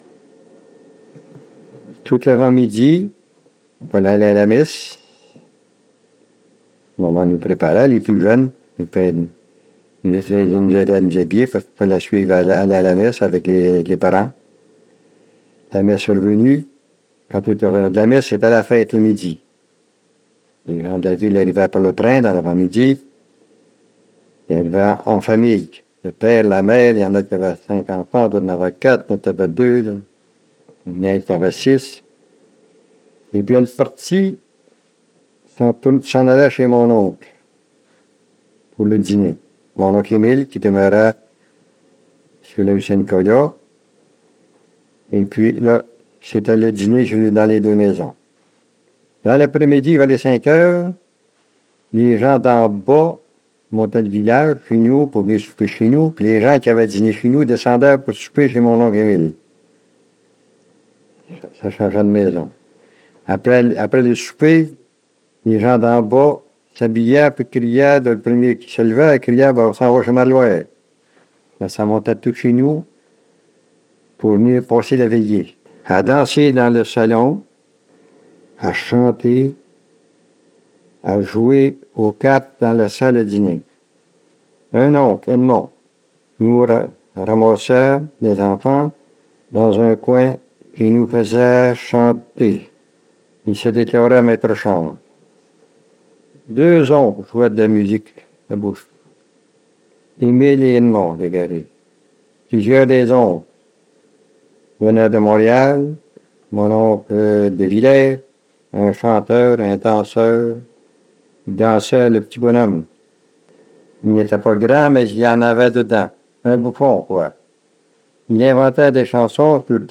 Audio excerpt: Interview